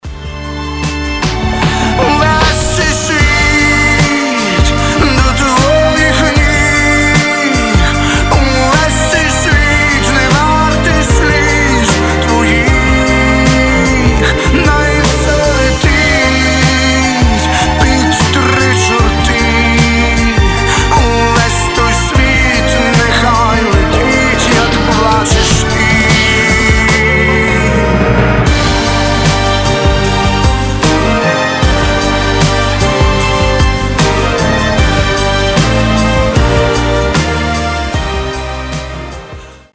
• Качество: 320, Stereo
мужской вокал
скрипка
романтичные
Красивая украинская баллада